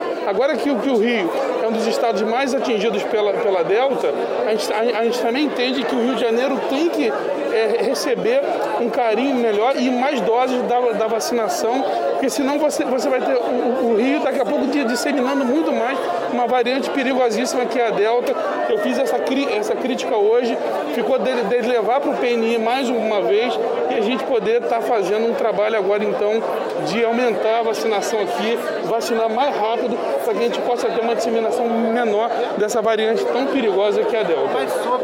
O governador Cláudio Castro, durante a solenidade de inauguração do novo plenário da Alerj, no centro do Rio, disse que cobrou do ministro da Saúde, Marcelo Queiroga, maior quantidade de doses da vacina contra a Covid-19 para o Estado do  Rio de Janeiro, que vem registrando vários casos da variante Delta.